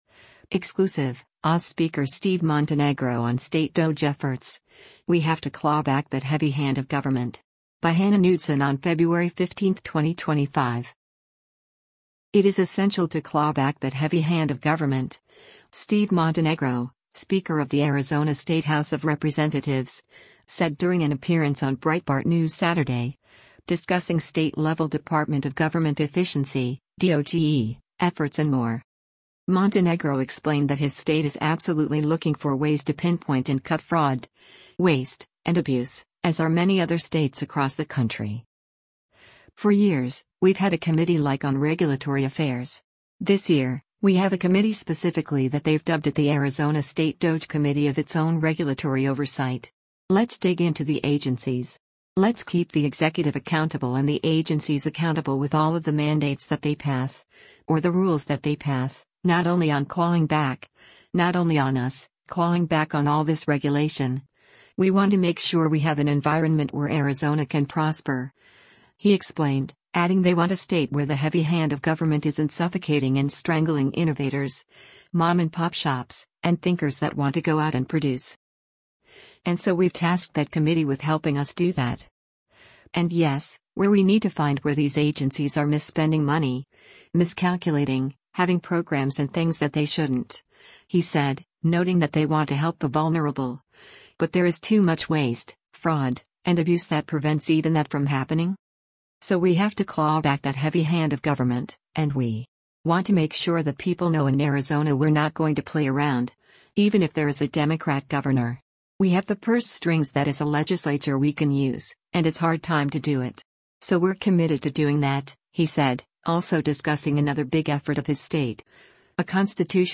It is essential to “claw back that heavy hand of government,” Steve Montenegro, speaker of the Arizona State House of Representatives, said during an appearance on Breitbart News Saturday, discussing state-level Department of Government Efficiency (DOGE) efforts and more.
Breitbart News Saturday airs on SiriusXM Patriot 125 from 10:00 a.m. to 1:00 p.m. Eastern.